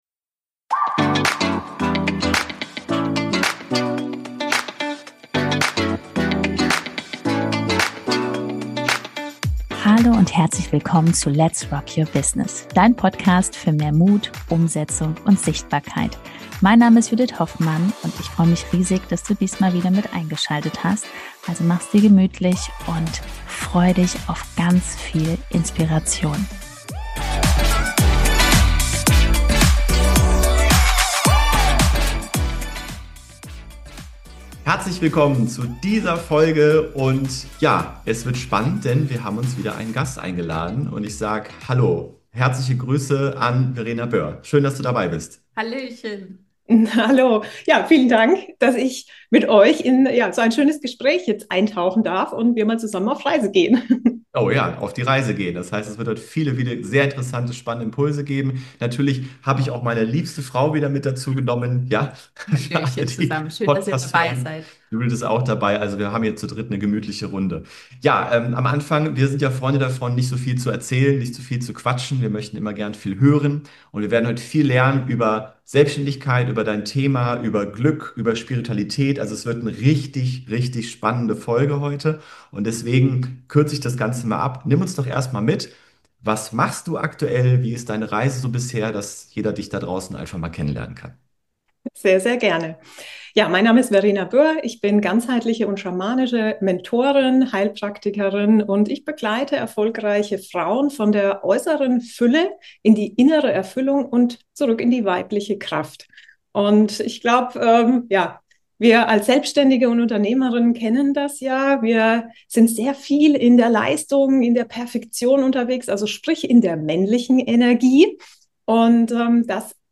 381 - Innerlich erfüllt, kraftvoll und zufrieden: Interview